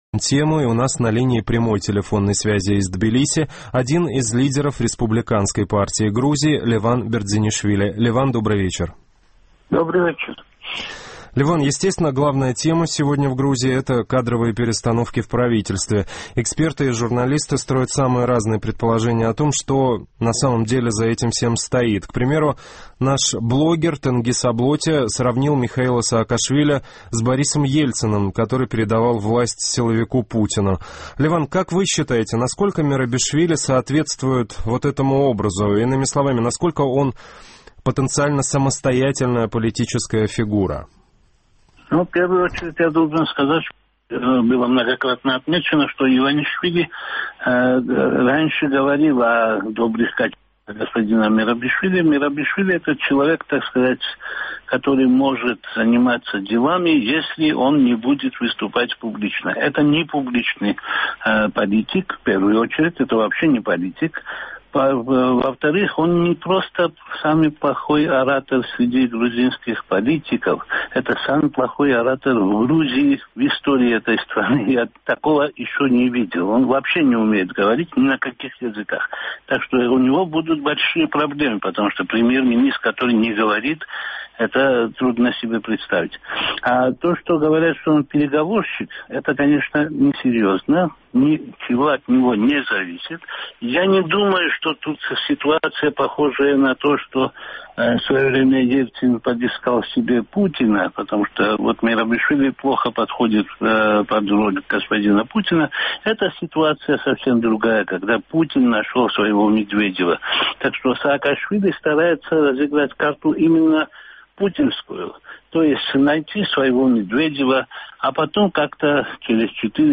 У нас на линии прямой телефонной связи из Тбилиси один из лидеров Республиканской партии Грузии Леван Бердзенишвили.